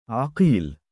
母音記号あり：عَقِيل [ ‘aqīl ] [ アキール ]
male_3aqiil.mp3